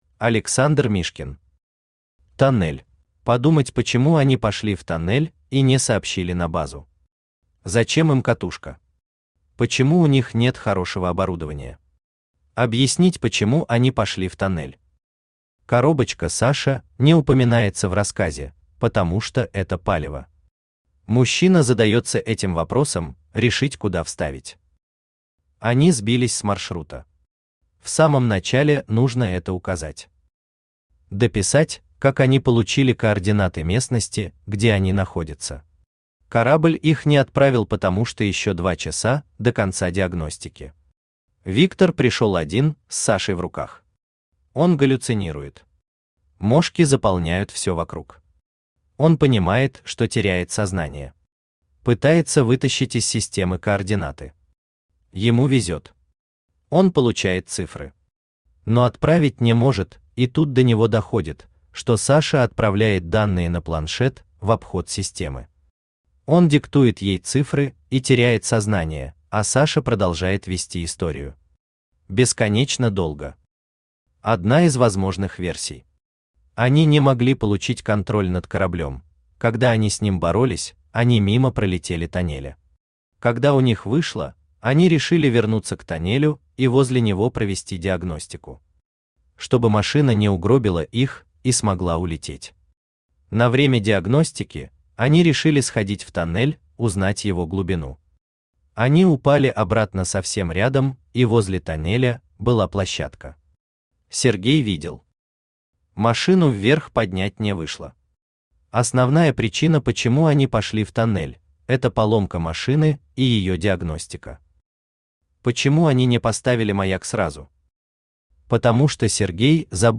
Аудиокнига Тоннель | Библиотека аудиокниг
Aудиокнига Тоннель Автор Александр Александрович Мишкин Читает аудиокнигу Авточтец ЛитРес.